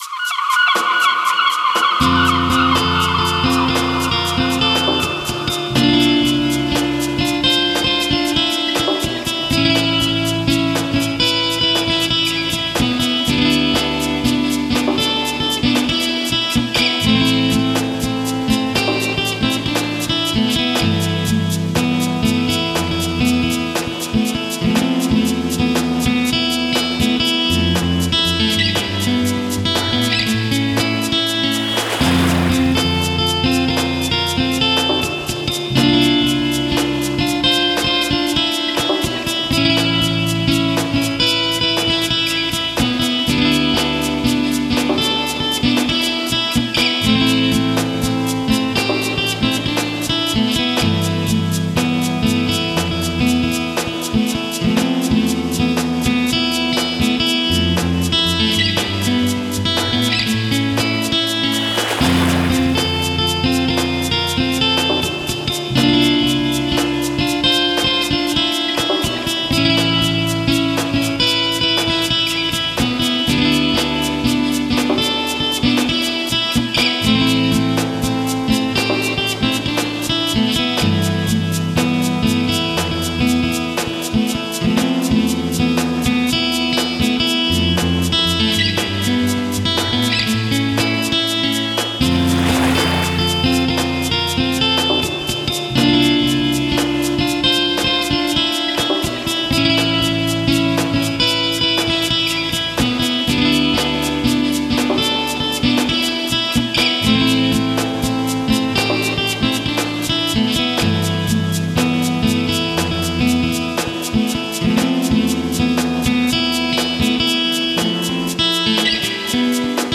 Musique zen